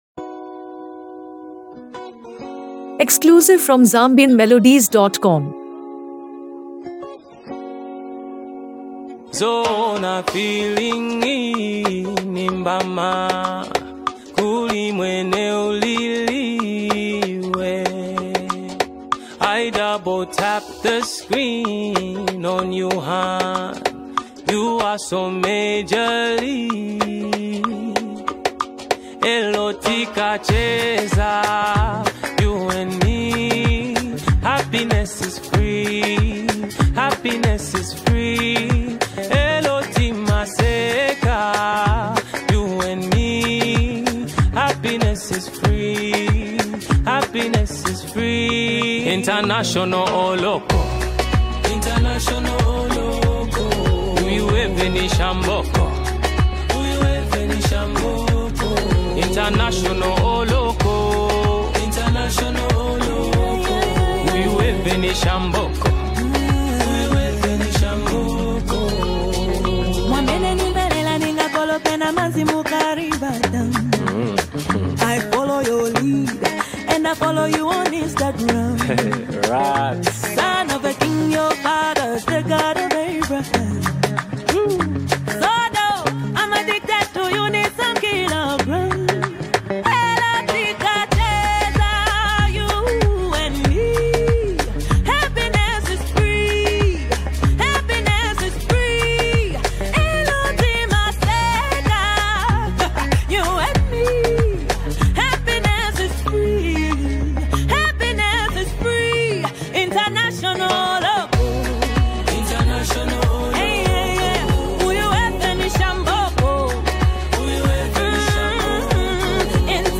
A Powerful Gospel Anthem from Zambia’s Power Couple